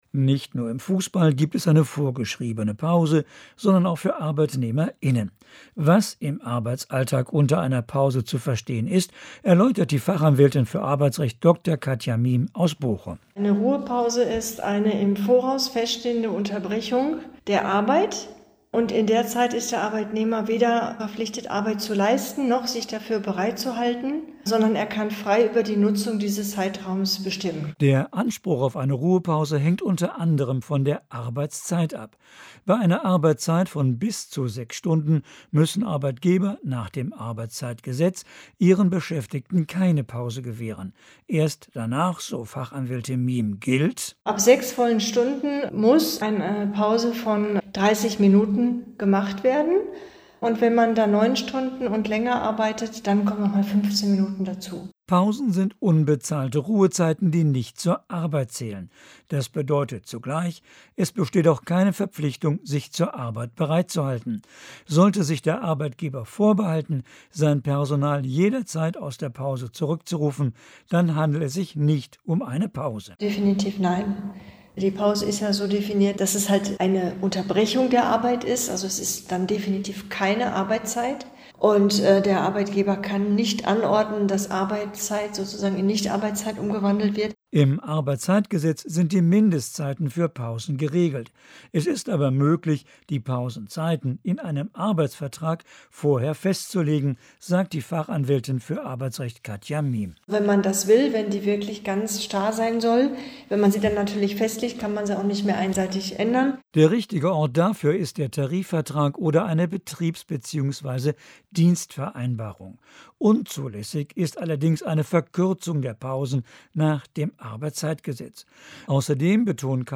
Im Folgenden finden Sie einige ausgewählte Radiointerviews: